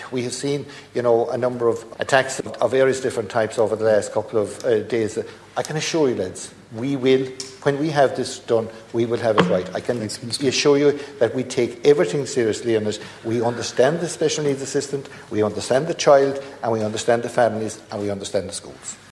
Junior Minister Michael Moynihan told the Dáil the Department of Education is fully engaged with the matter…………..